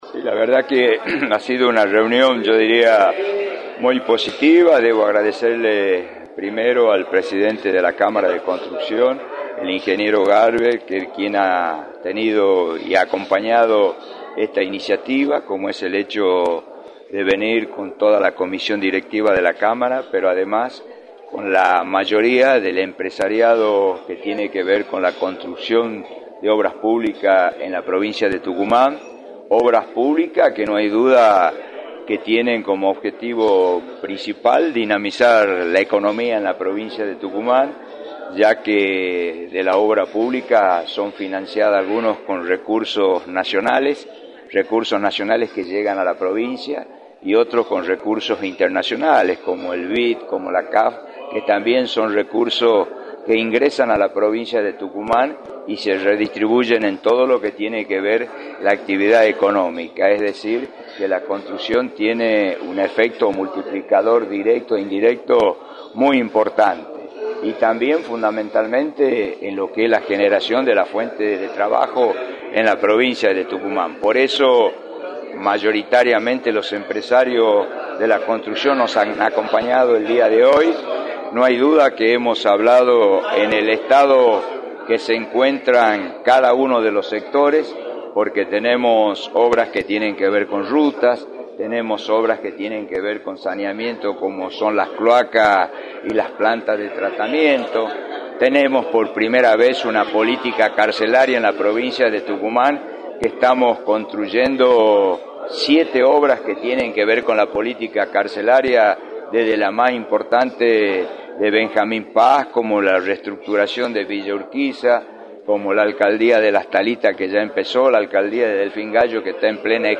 “No hay dudas que la obra pública tiene como objetivo principal dinamizar la economía en la provincia de Tucumán, ya que son financiadas, en algunos casos, con recursos nacionales que llegan a la provincia, y otros, con recursos internacionales, como el BID, como la CAF y que se redistribuyen en todo lo que tiene que ver la actividad económica, es decir que la construcción tiene un efecto multiplicador” señaló Osvaldo Jaldo en entrevista para “La Mañana del Plata”, por la 93.9.